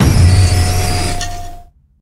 Cri de Pelage-Sablé dans Pokémon HOME.